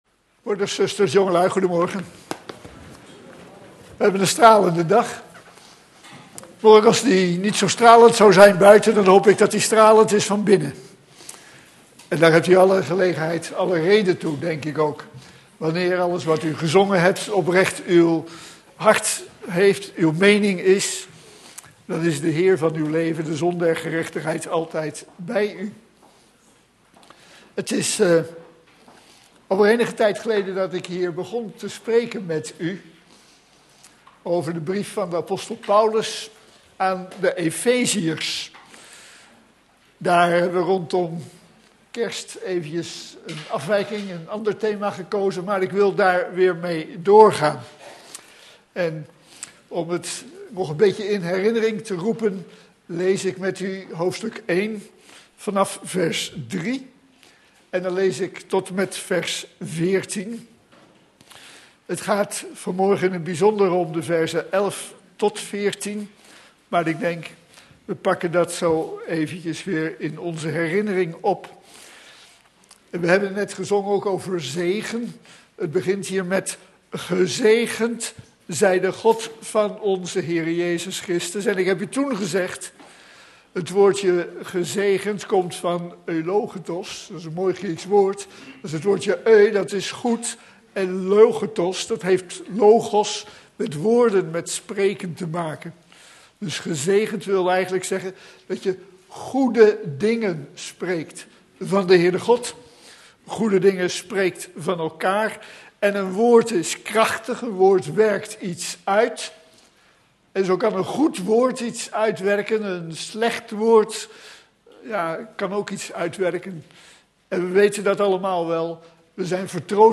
In de preek aangehaalde bijbelteksten (Statenvertaling)